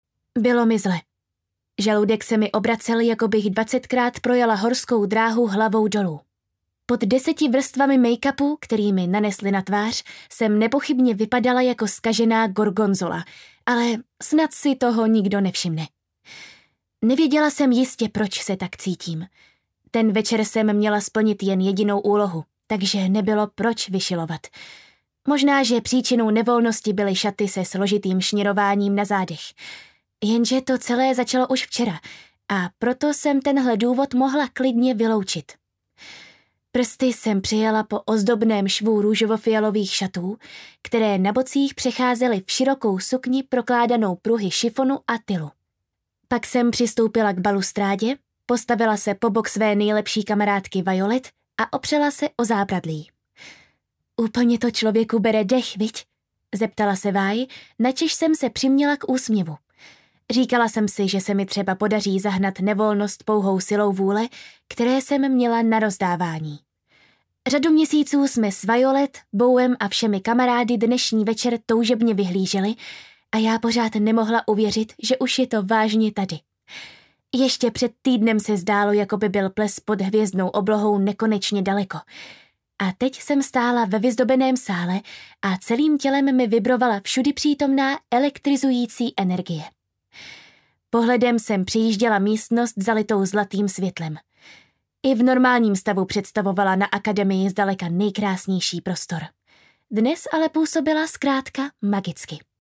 Audiokniha Ve spárech magie, kterou napsala Mona Kasten.
Ukázka z knihy
ve-sparech-magie-audiokniha